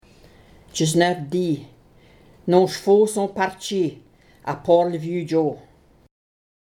Audio recording of F19d read in Michif by translator